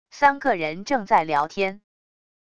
三个人正在聊天wav音频